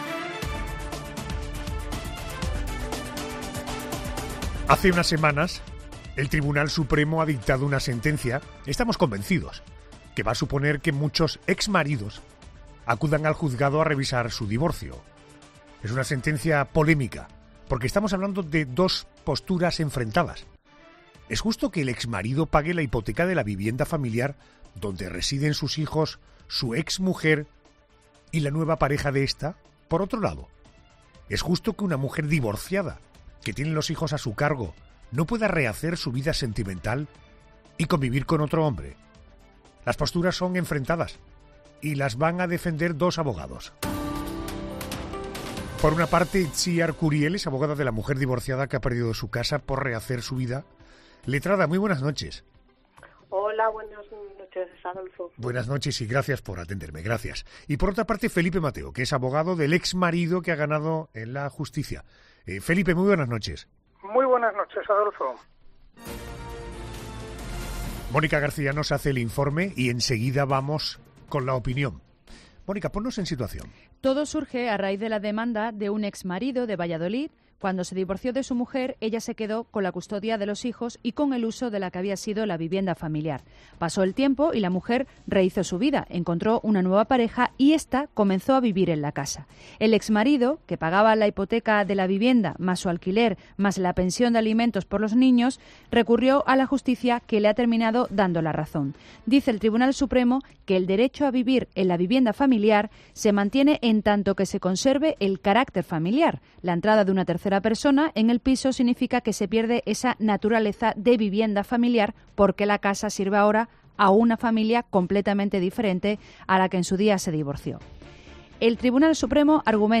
defienden sus posturas en el cara a cara de 'La Noche de COPE'